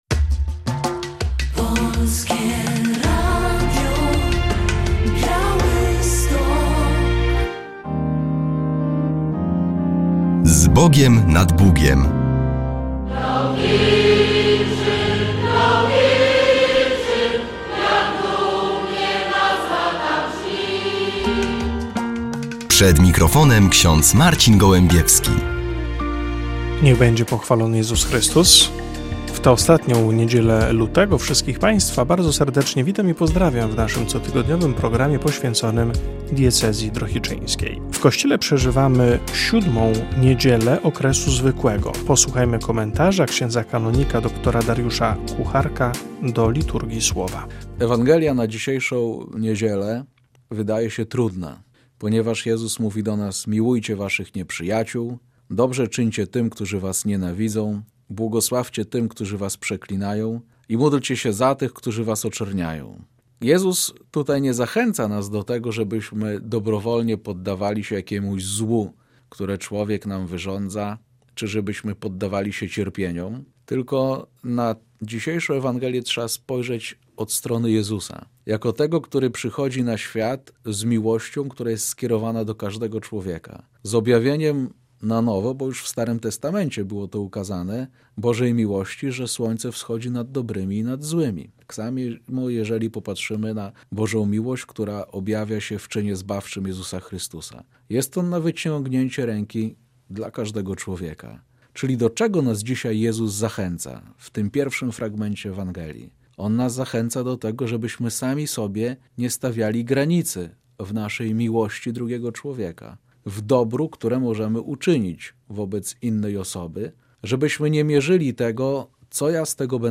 W audycji relacja z pierwszego diecezjalnego spotkania młodzieży "Płomień nadziei".